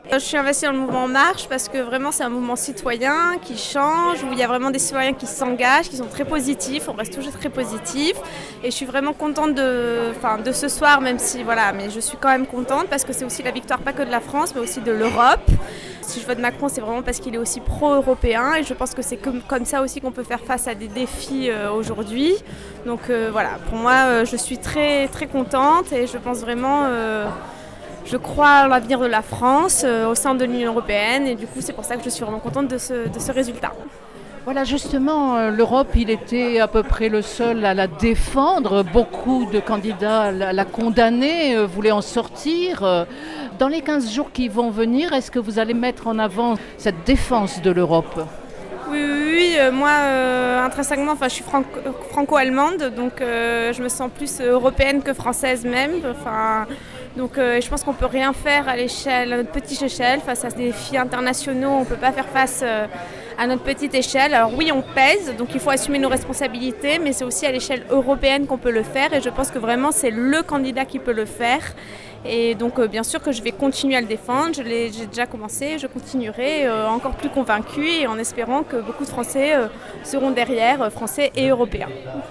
Marseille – Au QG d’En Marche la victoire est savourée: réactions